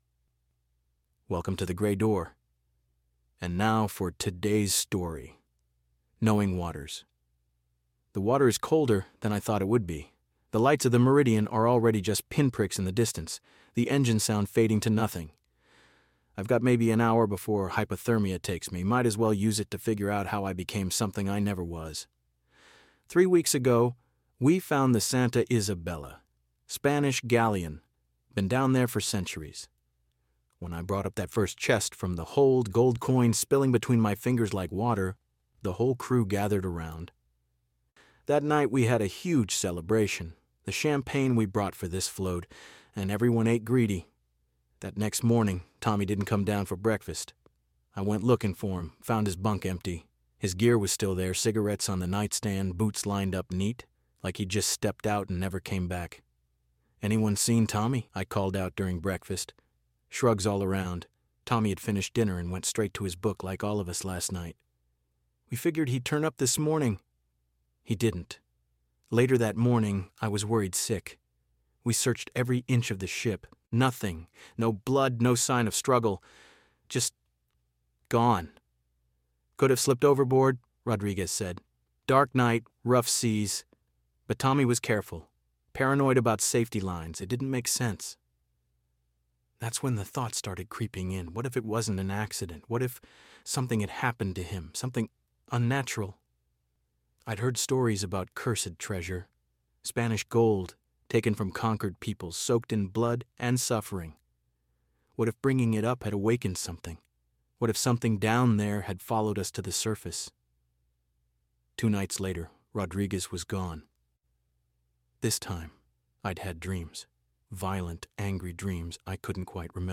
Category: Fiction
Was it a supernatural curse clinging to the gold, or a predator hiding among them? Recorded as he floats alone in the open ocean, this is one man's frantic account of paranoia, guilt, and the shocking truth about what really happened aboard the Meridian.